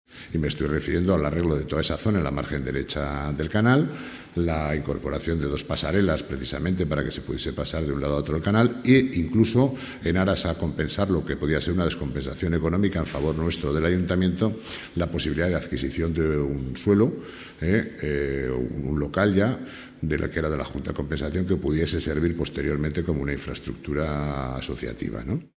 El Consejero de Urbanismo, Infraestructuras, Equipamientos y Vivienda, Carlos Pérez Anadón, detalla la naturaleza de los trabajos que se van a llevar a cabo: